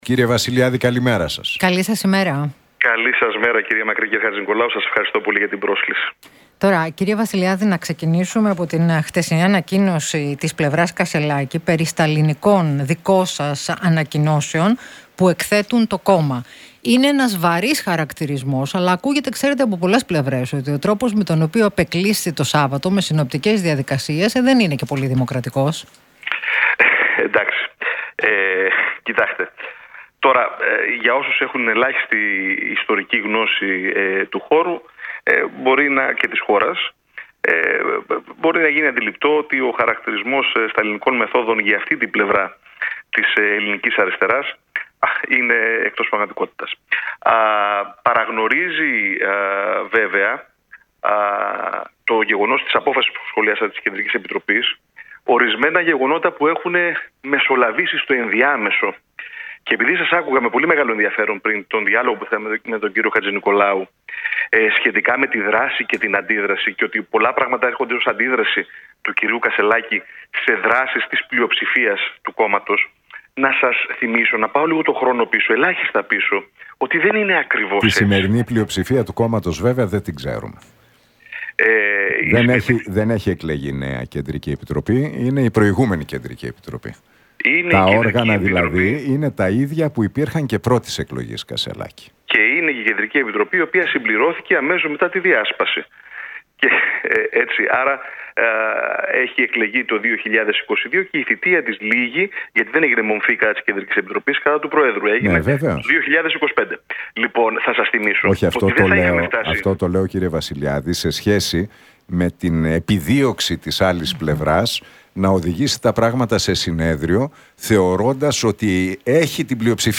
από τη συχνότητα του Realfm 97,8.